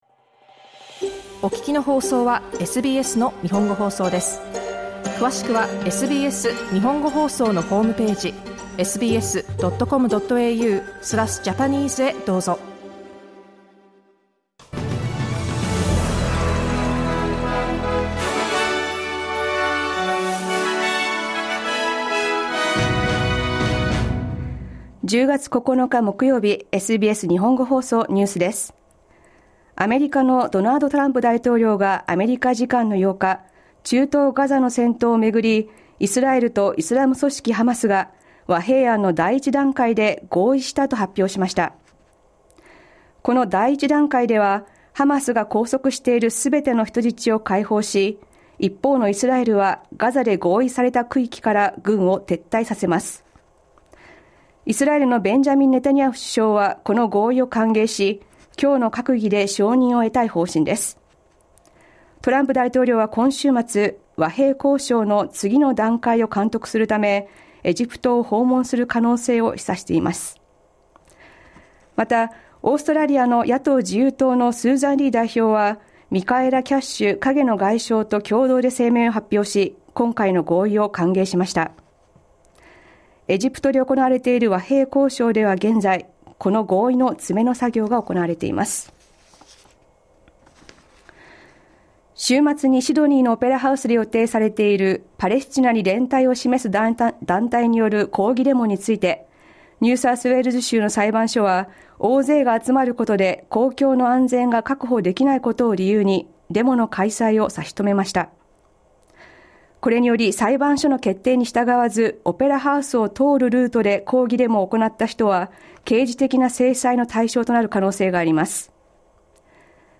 SBS日本語放送ニュース10月9日木曜日